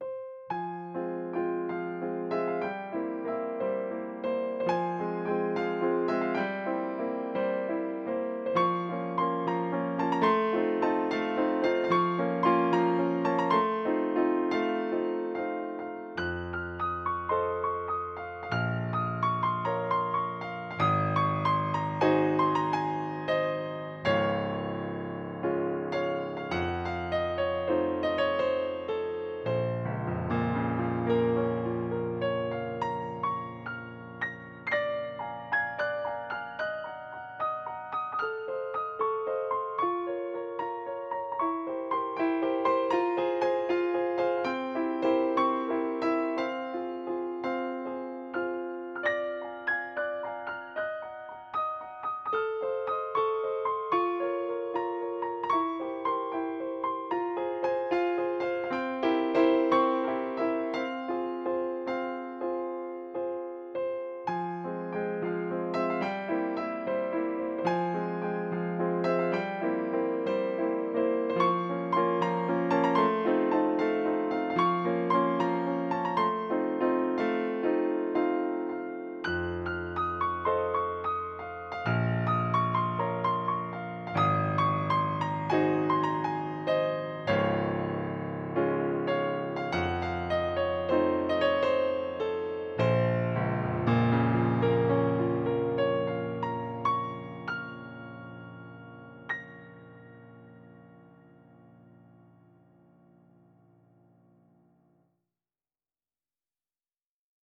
Mein Haupt-Hobby, Klavierkompositionen: